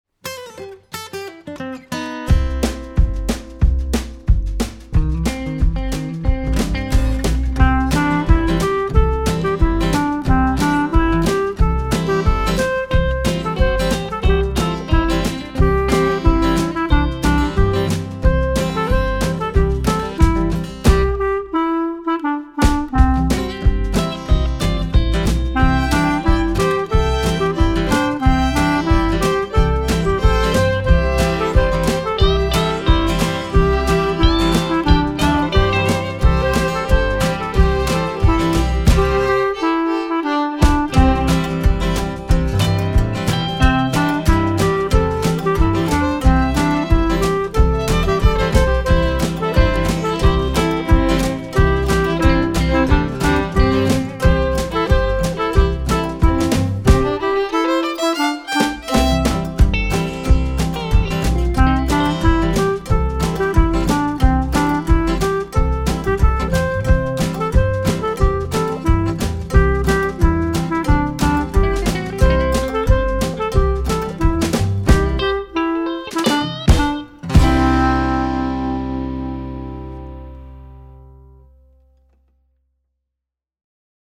Auf der Wiese - Das Marienkäferlein - Playback